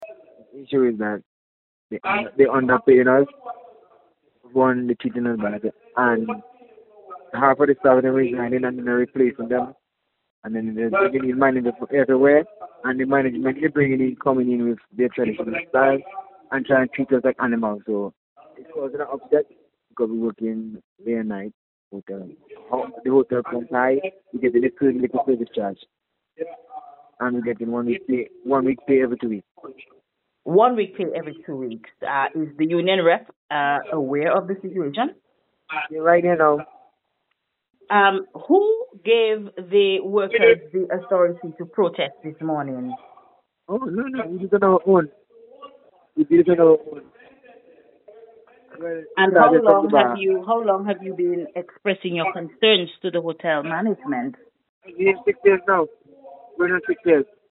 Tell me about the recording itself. Several dozen employees gathered on the hotel’s grounds to protest what they described as management’s blatant disregard for their urgent pleas for timely wage payments and improved working conditions STAFF-PROTEST.mp3